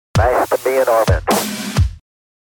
メインパート前のブレイク
このサンプル曲では、イントロが終わりメインのパートがはじまる直前に、1小節のブレイク（演奏が一瞬止まること）を入れています。
ここでは演奏を止めるだけでなく、そこに英語のスピーチとドラムループの一部分を組み合わせて装飾しています。
gb-sample-break-1.mp3